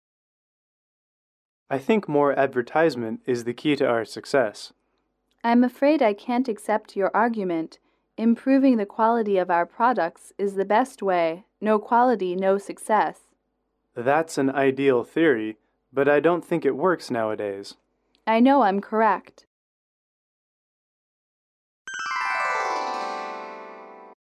英语主题情景短对话01-2：广告与产品质量（MP3）